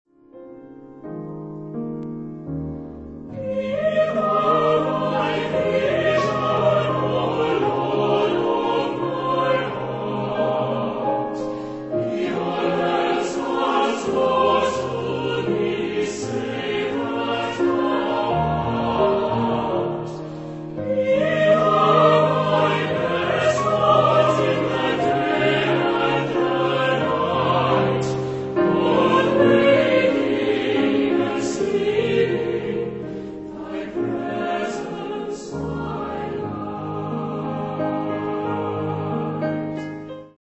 Type de matériel : Choeur et piano
Genre-Style-Forme : Motet ; Sacré
Caractère de la pièce : expressif
Type de choeur : SATB  (4 voix mixtes )
Solistes : Soprano  (1 soliste(s))
Instruments : Orgue (1)
Tonalité : la bémol majeur